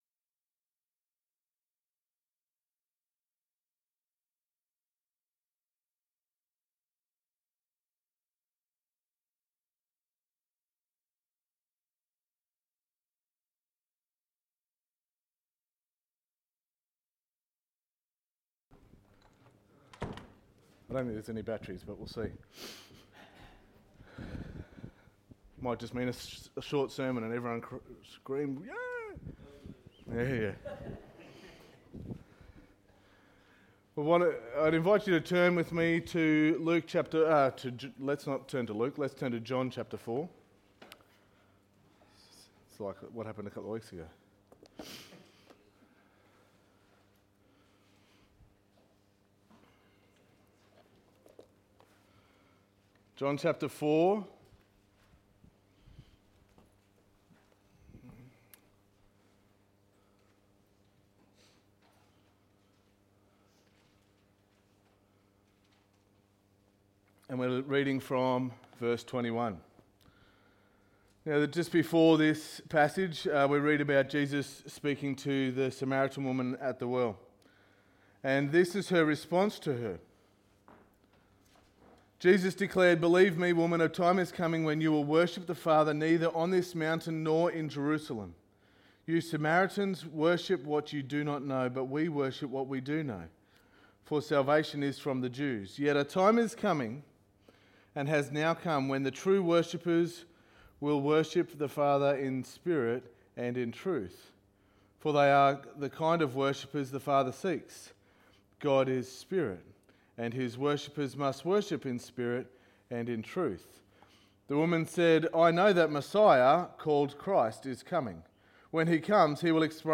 Sermon 18.08.19